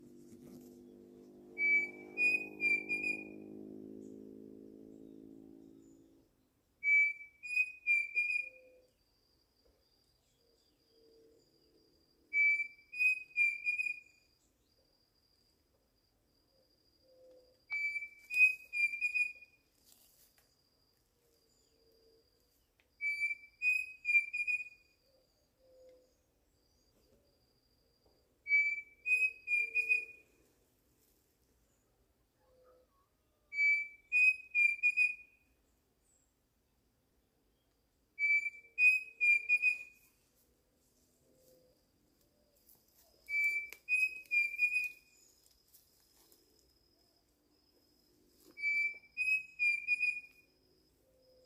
Pavonine Cuckoo (Dromococcyx pavoninus)
Life Stage: Adult
Detailed location: Departamento Guaraní - Biosfera Yabotí
Condition: Wild
Certainty: Observed, Recorded vocal